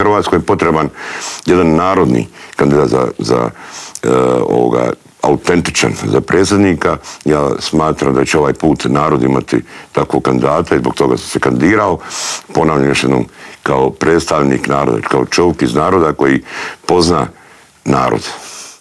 Miro Bulj izjavio je u studiju Media servisa da se kandidirao za predsjednika države jer mu je ‘‘puna kapa‘‘ gledati kako Hrvatska demografski tone i kako se vladajući odnose prema Hrvatskoj vojsci.